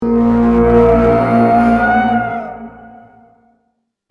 Scary Halloween Intro Sound Button - Free Download & Play